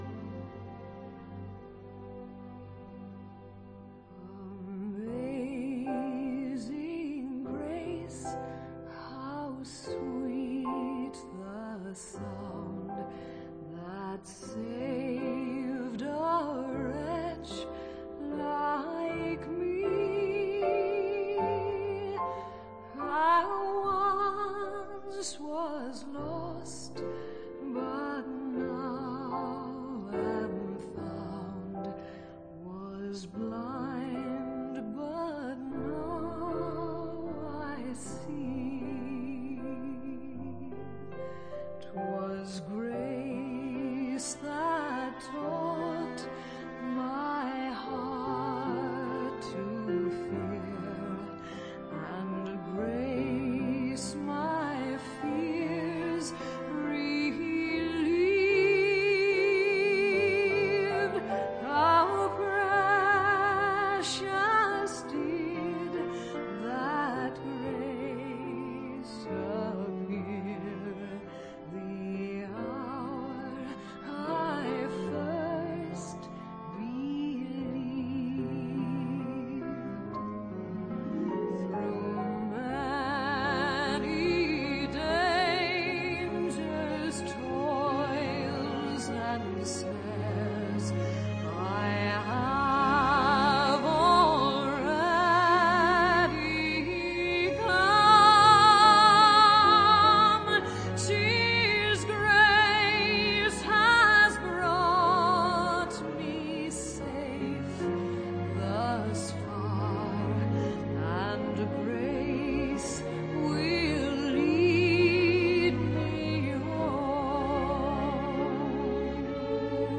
但是对其中的黑人合唱和音吟唱风格非常佩服。